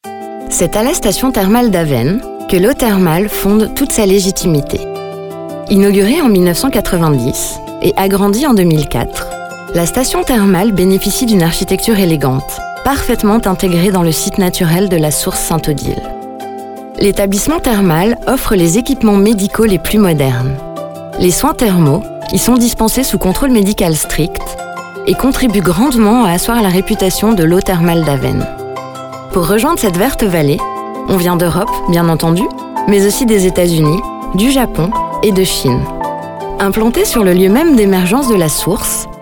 Narration
Artiste inspirée, attentive, disponible et polyvalente, avec une voix grave-médium.
Cabine DEMVOX, micro Neumann TLM 103, Scarlett 4i4 et ProTools Studio sur un Mac M1